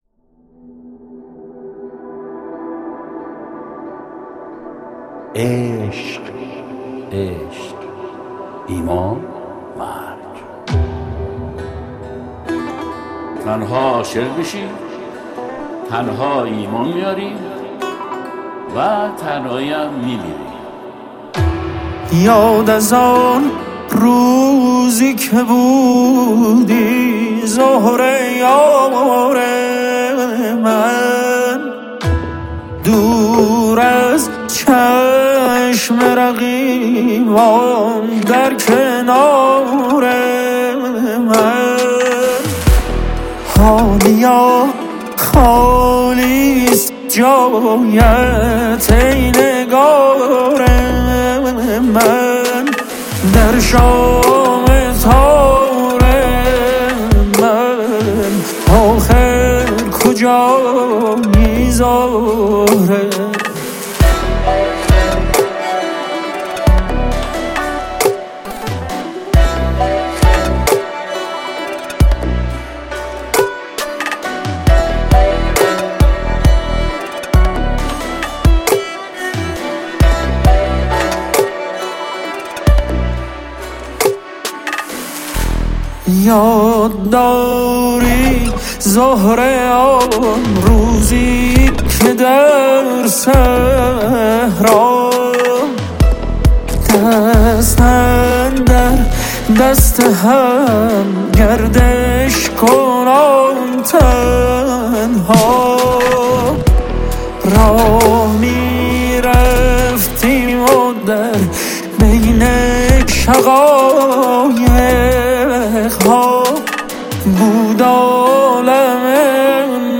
دانلود آهنگ جدید ریمیکس